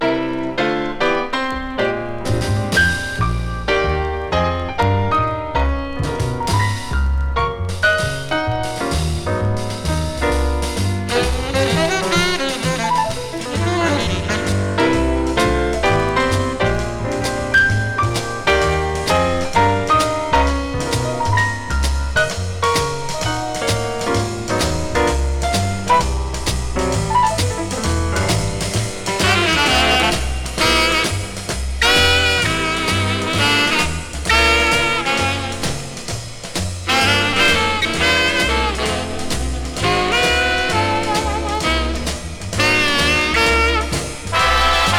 Jazz　USA　12inchレコード　33rpm　Mono